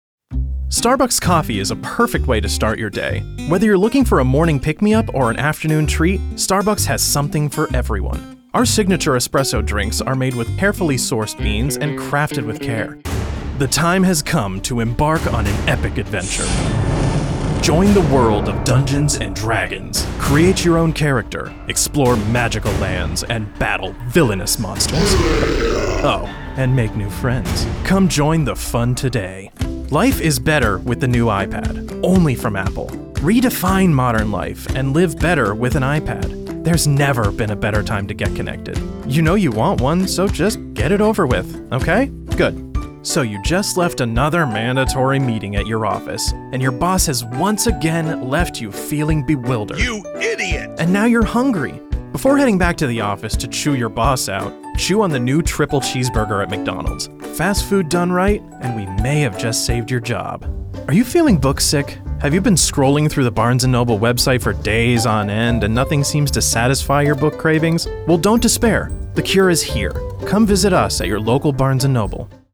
Young Adult, Adult
british rp | character
new york | character
standard us | natural
COMMERCIAL 💸
conversational
quirky
smooth/sophisticated
warm/friendly